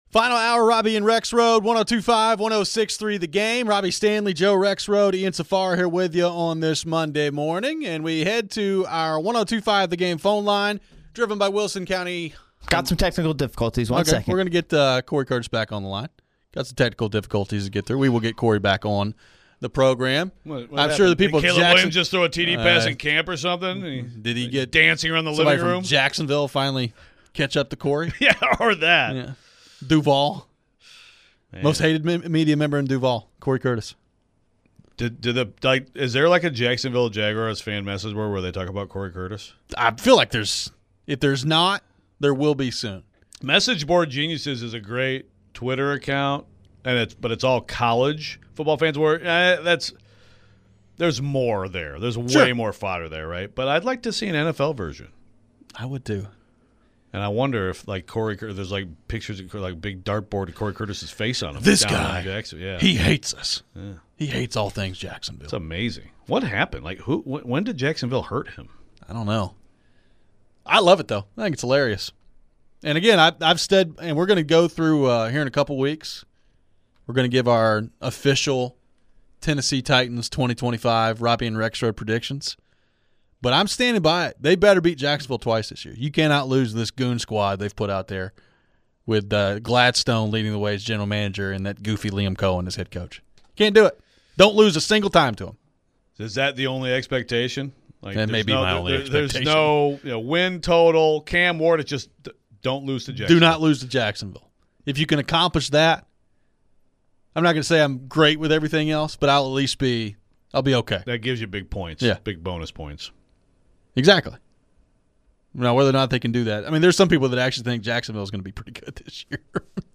Only Cam Ward and Tyler Shough are the only two rookie QBs who project to start this season. What does that mean for the preseason playing time for Ward? We close out the show with your phones.